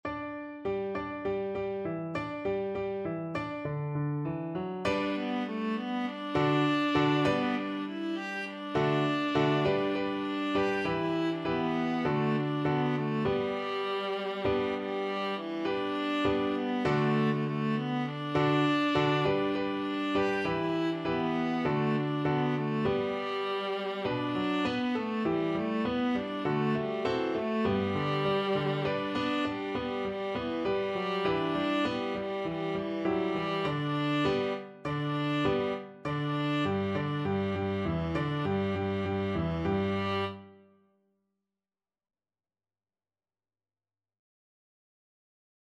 Traditional Trad. Feng Yang Viola version
Viola
G major (Sounding Pitch) (View more G major Music for Viola )
4/4 (View more 4/4 Music)
Joyfully =c.100
Chinese